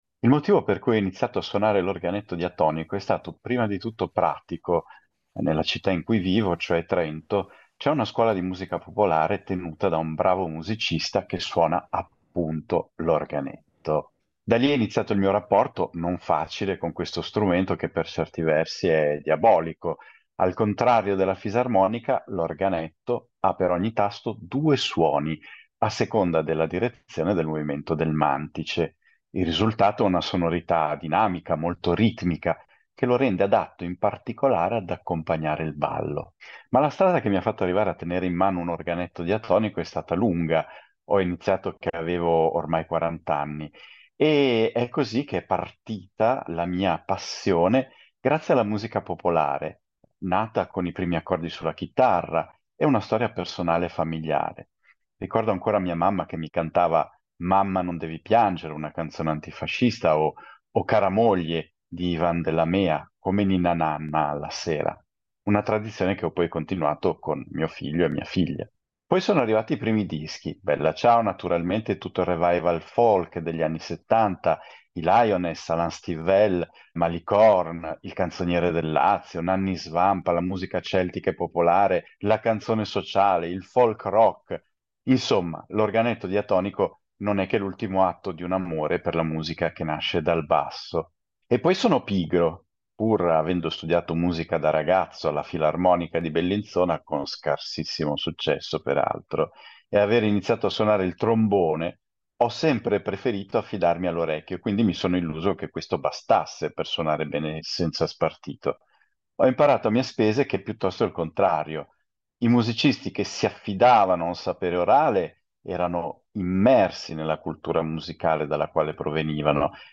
Lezioni di musica - Organetto diatonico